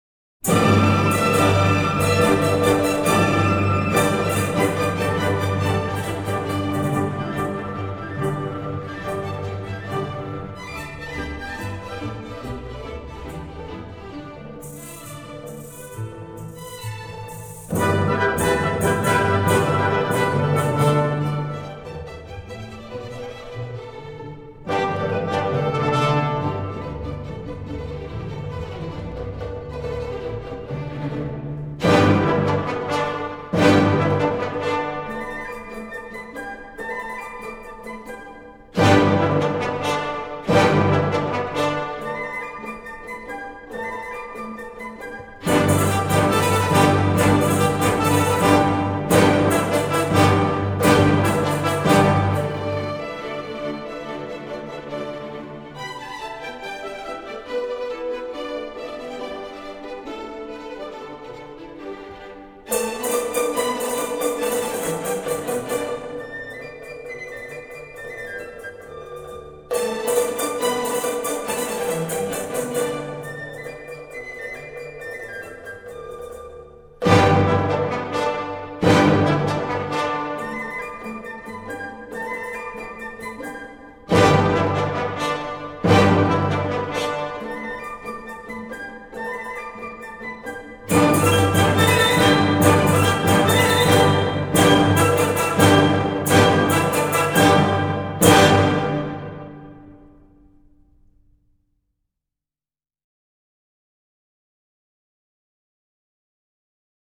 中国管弦乐及小品
这张精心制作的专辑在录音、数码处理上均为上乘，既体现出管弦乐恢弘的壮丽，也体现出民乐婉转的悠扬。
采用民间乐曲的多段体曲式